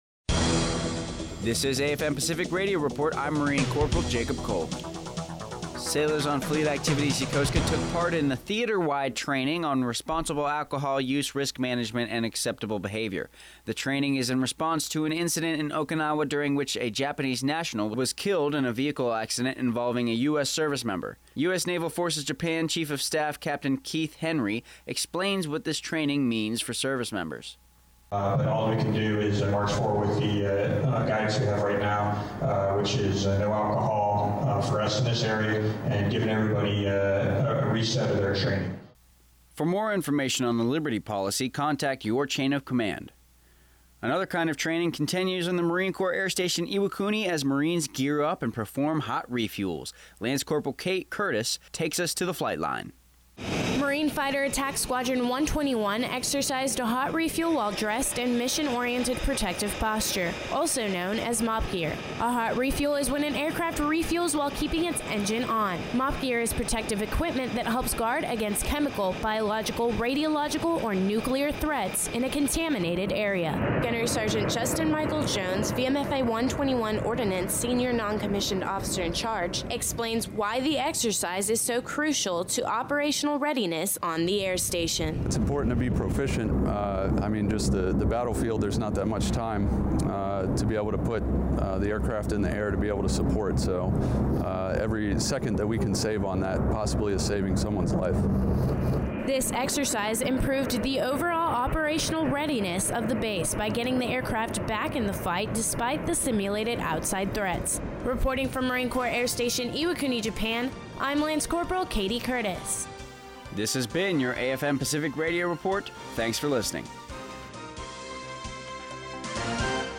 AFN Pacific Radio Report 23 November 2017